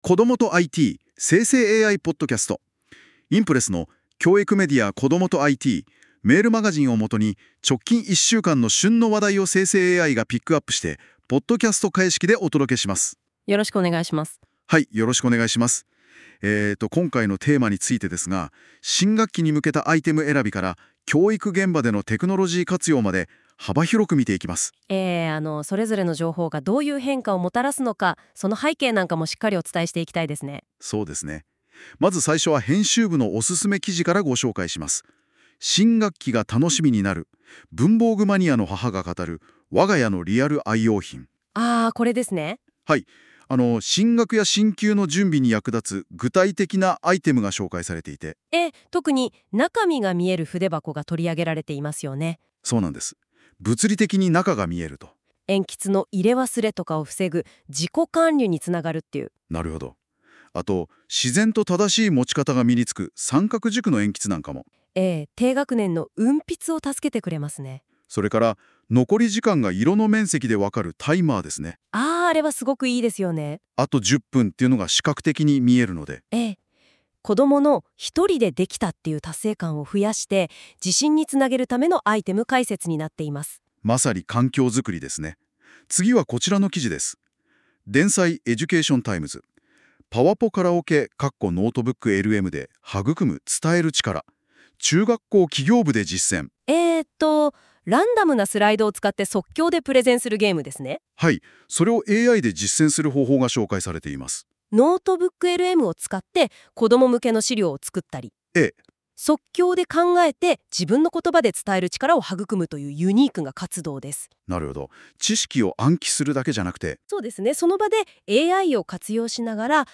この記事は、『こどもとIT』メールマガジンを元に、先週１週間の旬の話題をNotebookLMでポッドキャストにしてお届けする、期間限定の実験企画です。 ※生成AIによる読み上げは、不自然なイントネーションや読みの誤りが発生します。 ※この音声は生成AIによって記事内容をもとに作成されています。